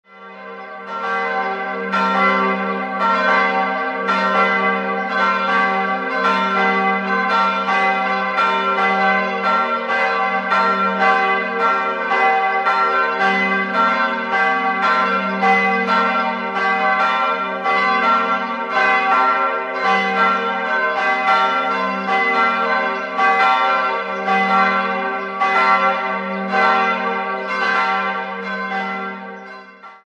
Die große und die kleine Glocke wurden 1957 von Friedrich Wilhelm Schilling gegossen und wiegen 916 und 338 kg. Die mittlere mit ihrem eigenen Klangbild dürfte wohl in Berching hergestellt worden sein und gehört zu den ältesten Glocken des Landkreises Neumarkt: Vermutlich wurde sie schon Anfang des 14. Jahrhunderts gegossen.